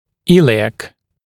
[‘ɪlɪæk][‘илиэк]подвздошный, относящийся к подвздошной кости